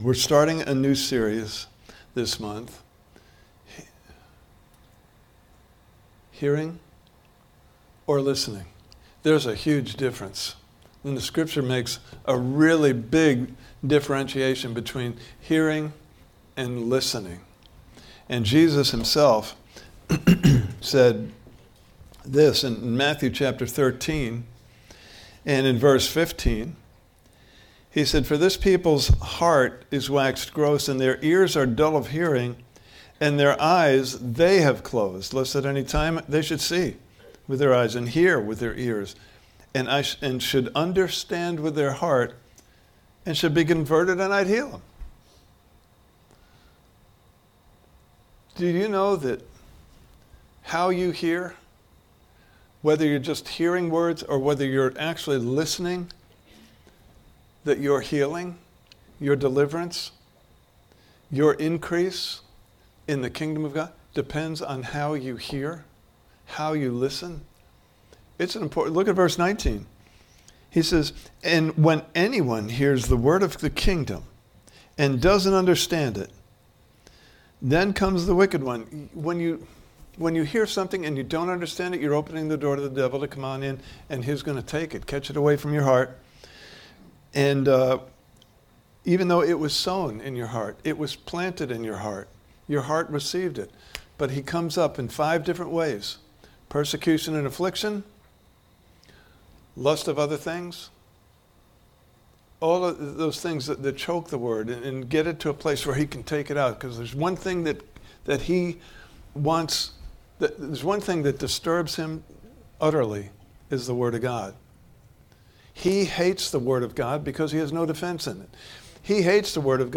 Series: Are You Hearing or Listening? Service Type: Sunday Morning Service « Part 3: What’s Inside Will Show Outside!